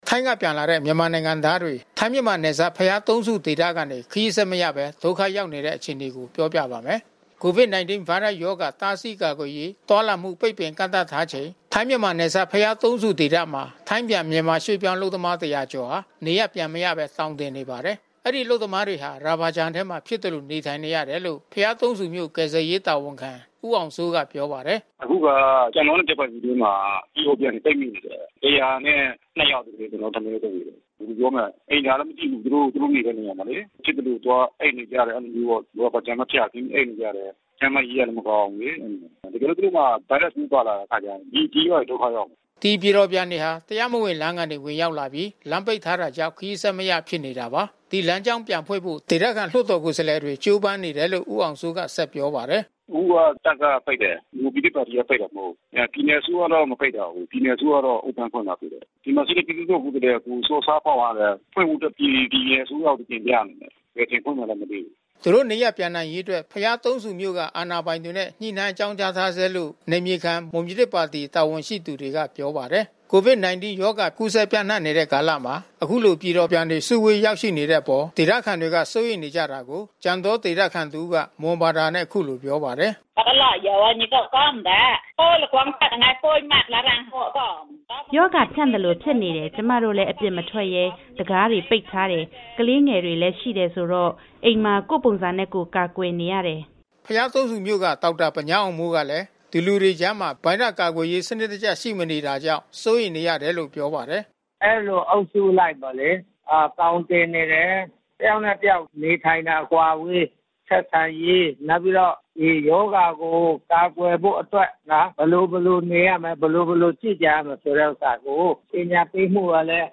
COVID-19 ရောဂါ ကူးစက်ပျံ့နှံ့နေတဲ့ကာလမှာ အခုလို ပြည်တော်ပြန်တွေ စုဝေးရောက်ရှိနေတဲ့အပေါ် ဒေသခံတွေက စိုးရိမ်နေကြတာကို ကြံတောရွာ ဒေသခံတဦးက မွန်ဘာသာနဲ့ အခုလိုပြောပါတယ်။